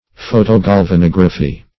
Search Result for " photogalvanography" : The Collaborative International Dictionary of English v.0.48: Photogalvanography \Pho`to*gal`va*nog"ra*phy\, n. [Photo- + galvanography.] The art or process of making photo-electrotypes.